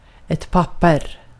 Ääntäminen
IPA : /ˈdɒkjʊmənt/